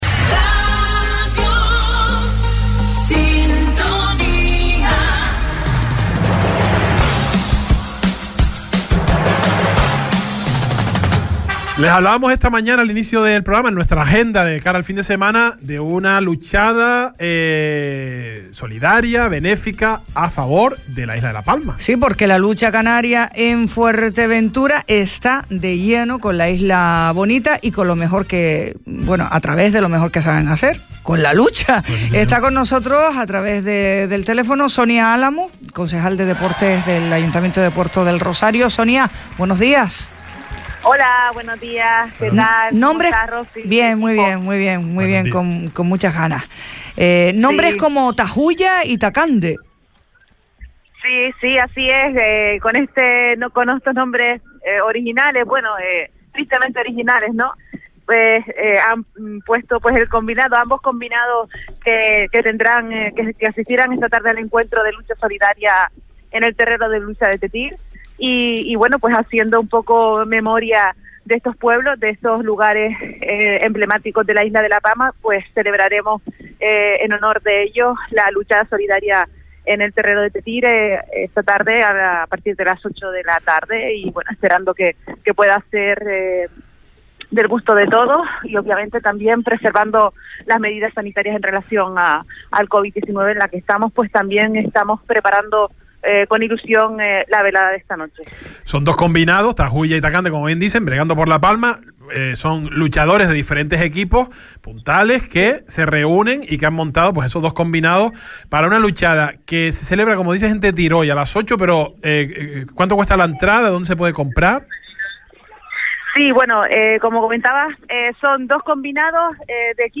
El Salpicón | Entrevista a Sonia Álamo, concejala de Deportes de Puerto Del Rosario – 11.12.21 Deja un comentario